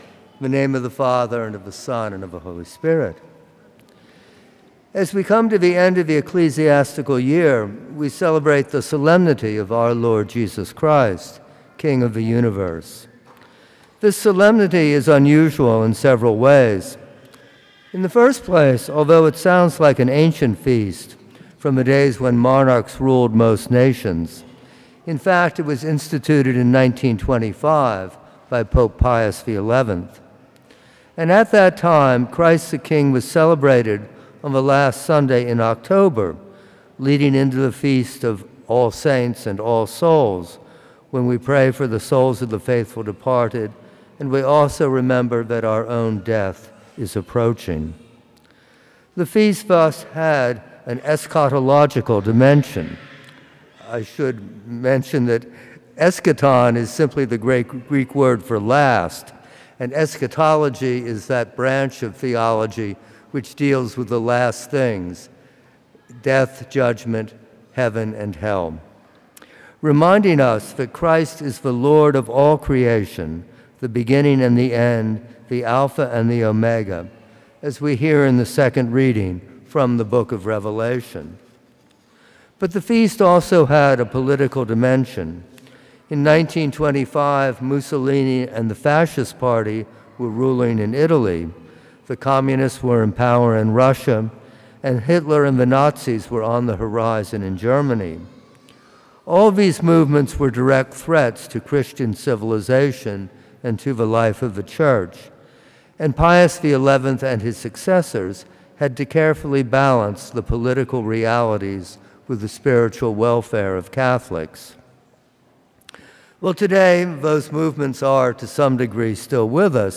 Homilies - Prince of Peace Catholic Church & School